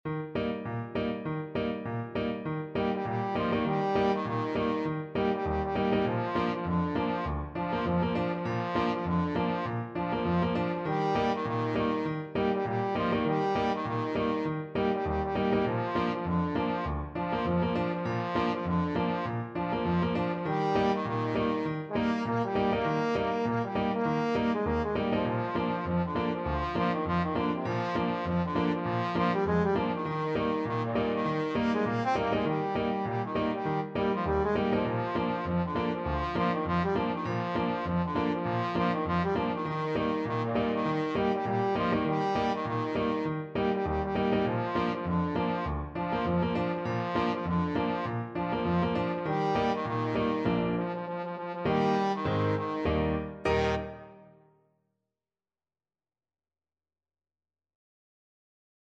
Trombone
Eb major (Sounding Pitch) (View more Eb major Music for Trombone )
Two in a bar with a light swing =c.100
Traditional (View more Traditional Trombone Music)
tit_galop_TBNE.mp3